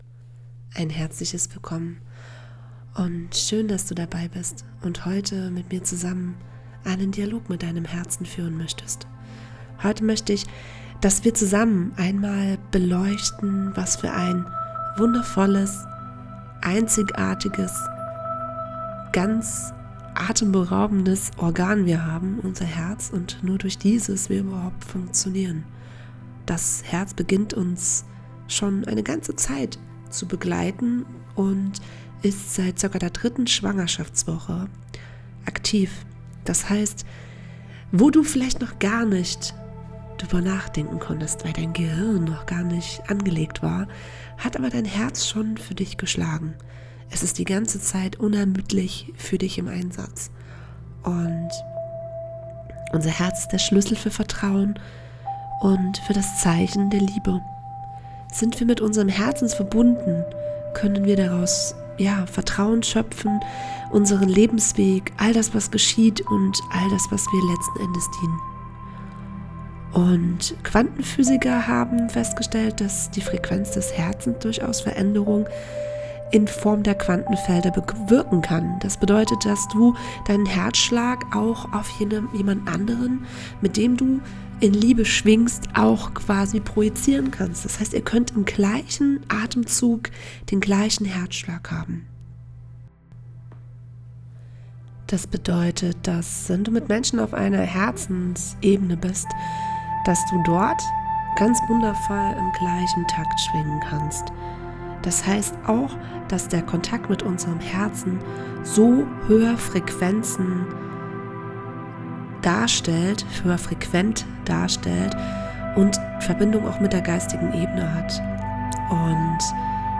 🎧 In dieser besonderen Podcast-Folge lade ich dich zu einer tiefen und geführten Meditation ein, die dich mit der Stimme deines Herzens verbindet.